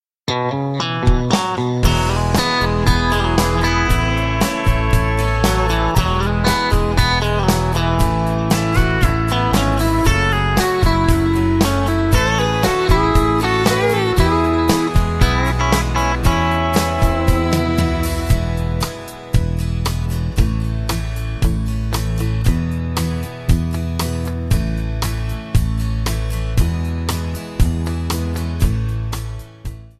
MPEG 1 Layer 3 (Stereo)
Backing track Karaoke
Country, 2000s